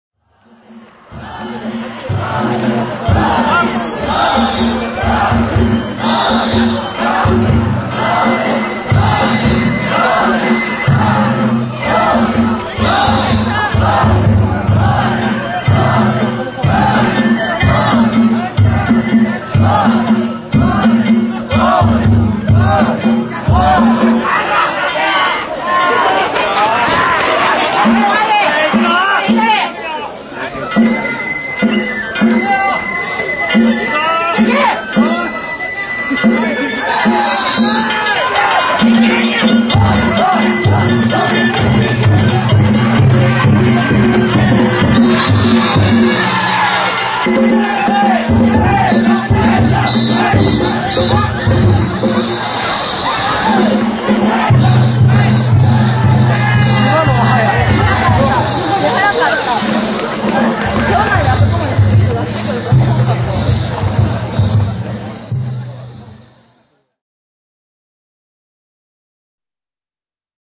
南上町地車お披露目曳行
平成２８年６月２６日、岸和田市の南上町地車お披露目曳行を見に行ってきました。
この後すぐ、鳴り物が始まりました。